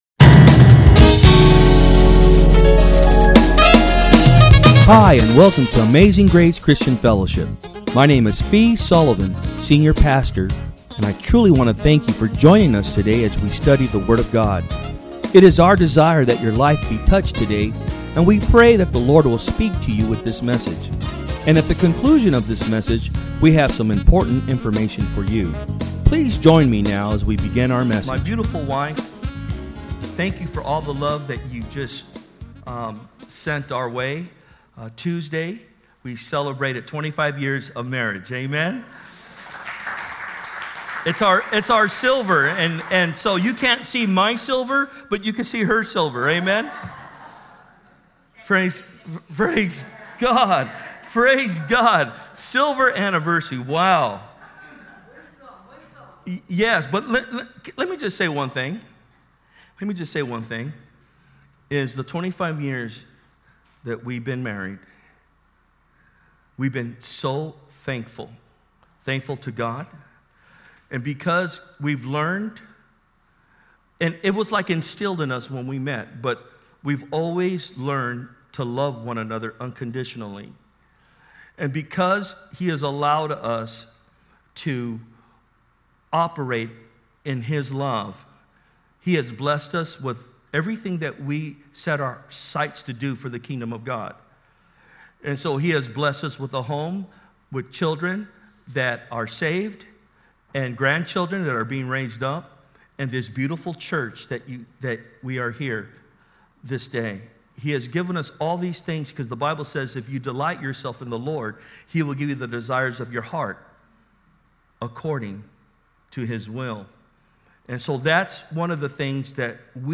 Message
From Service: "Sunday Am"